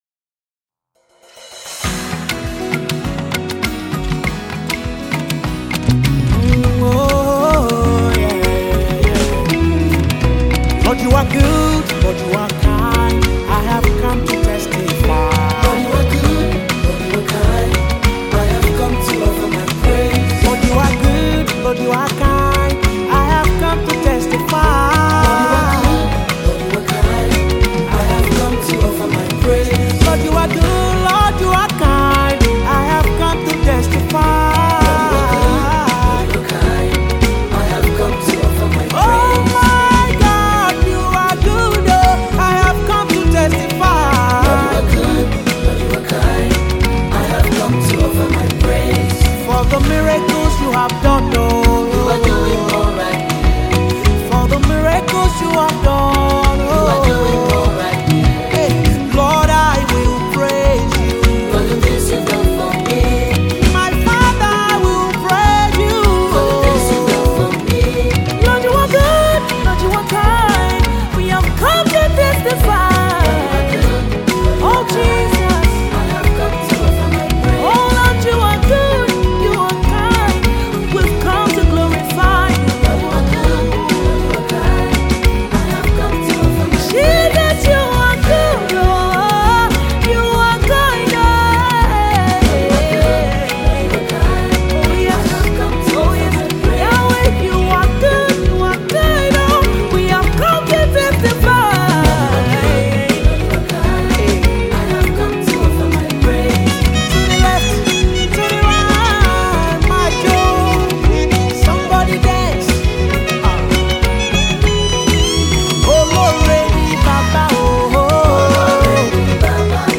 Download Latest Gospel Songs
featuring the powerful vocals